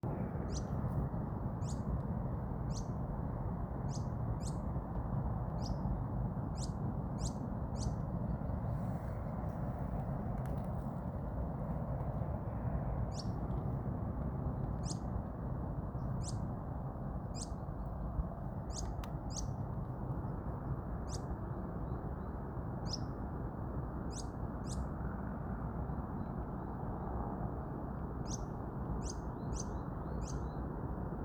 Sharp-billed Canastero (Asthenes pyrrholeuca)
Location or protected area: Reserva Ecológica Ciudad Universitaria - Costanera Norte (RECU-CN)
Condition: Wild
Certainty: Observed, Recorded vocal
Canastero-Coludo.mp3